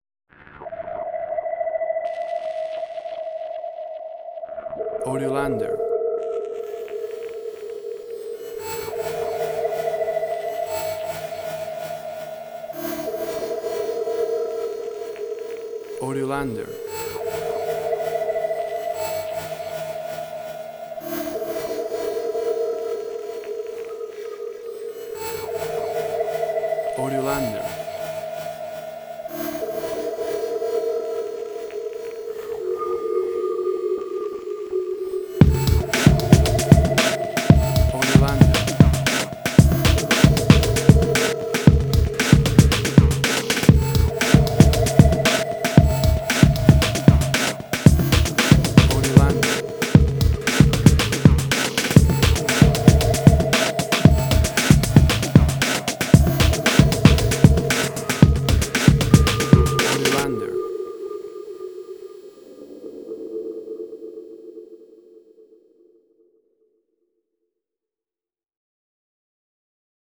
IDM, Glitch.
Tempo (BPM): 115